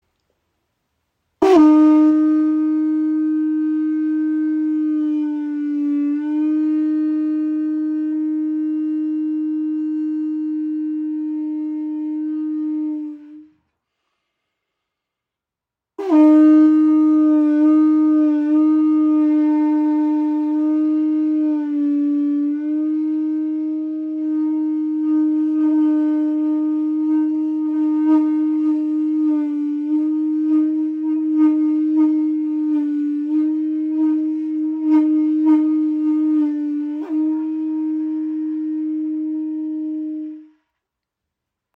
Muschelhorn | Pūtātara | Shankha | Conch Shell | Kavadi | Conque | ca. 24 cm
Lässt sich mit Zirkularatmung lange spielen.